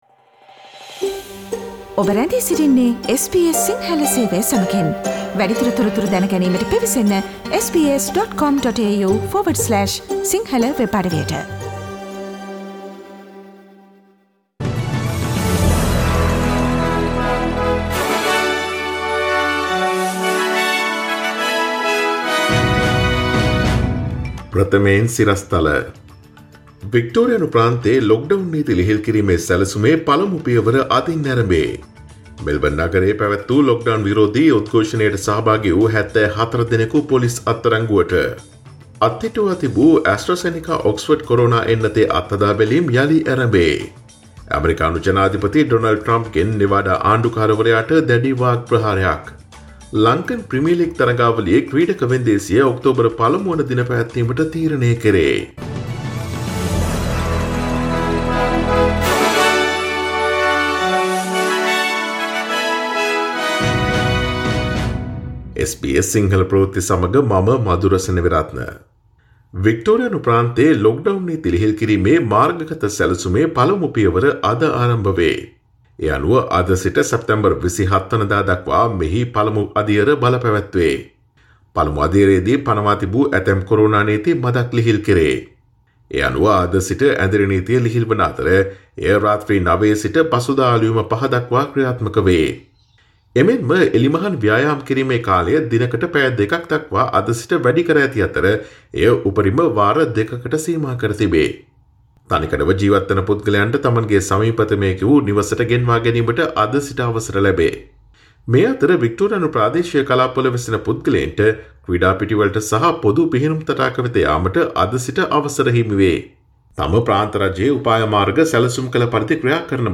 Daily News bulletin of SBS Sinhala Service: Monday 14 September 2020
Today’s news bulletin of SBS Sinhala Radio – Monday 14 September 2020 Listen to SBS Sinhala Radio on Monday, Tuesday, Thursday and Friday between 11 am to 12 noon